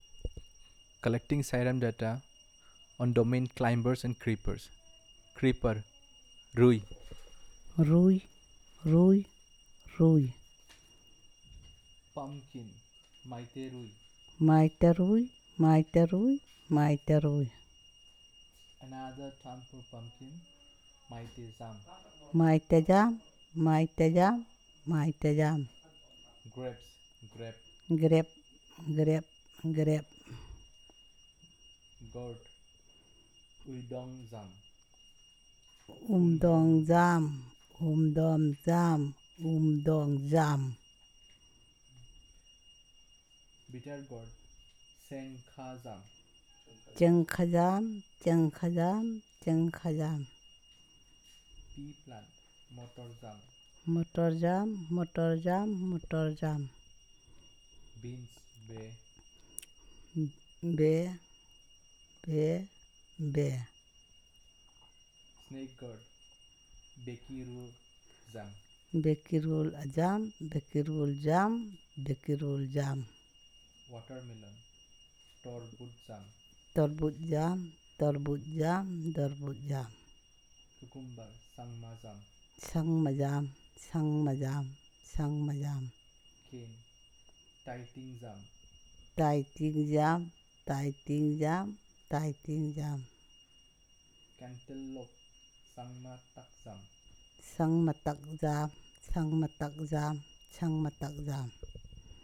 Elicitation of words about climbers and creepers